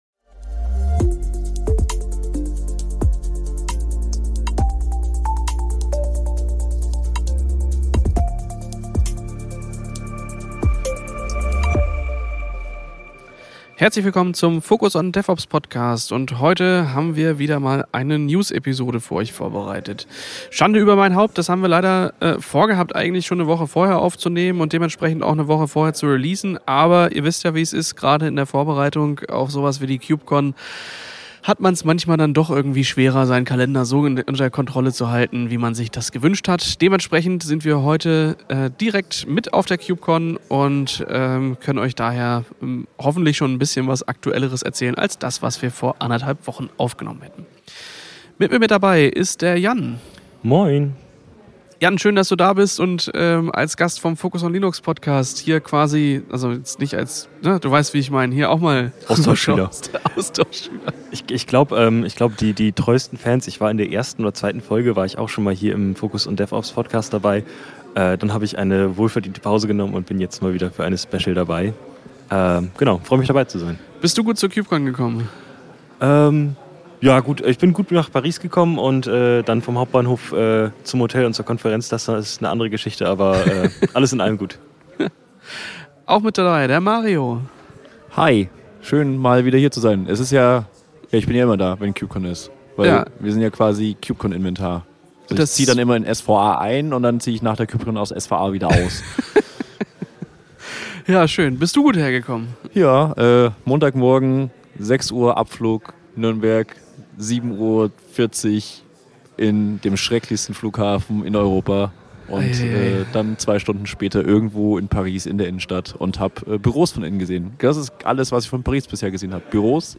Beschreibung vor 2 Jahren In dieser Ausgabe des Focus On DevOps Podcasts berichten wir direkt von der KubeCon + CloudNative Europe aus Paris über Neuigkeiten und Entwicklungen im Kubernetes Ökosystem.